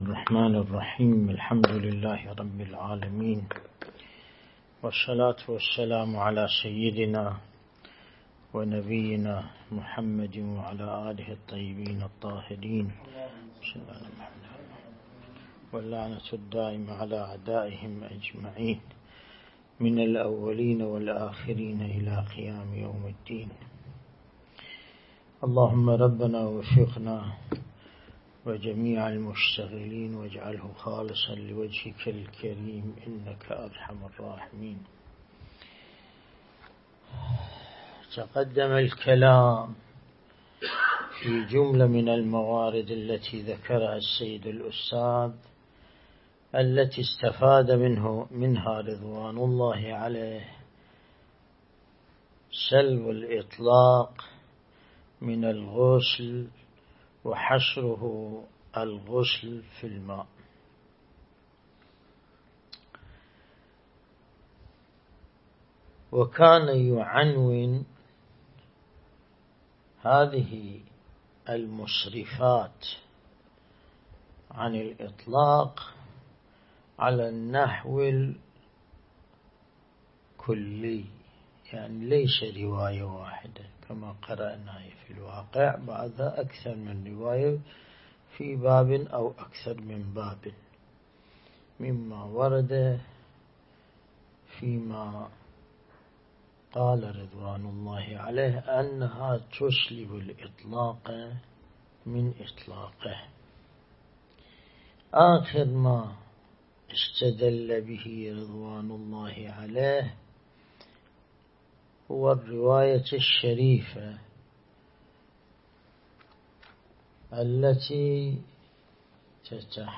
الدرس الاستدلالي شرح بحث الطهارة من كتاب العروة الوثقى